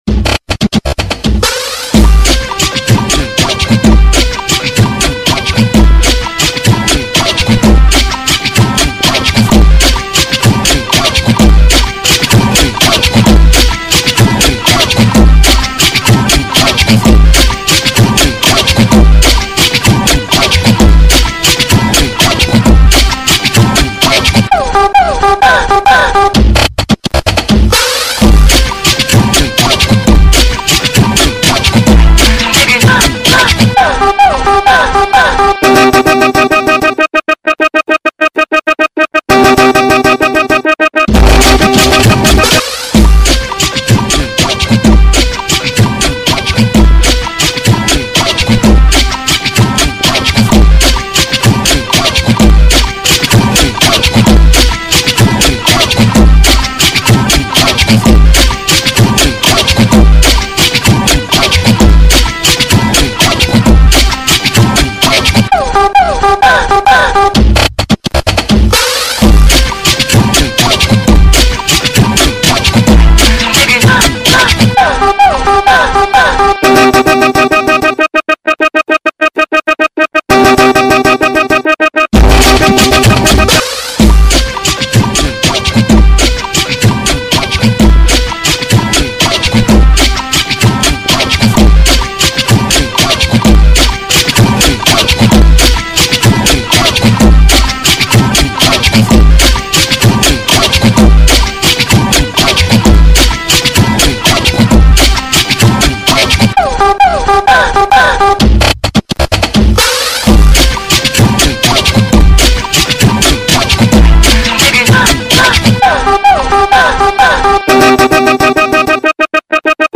Composição: Remix.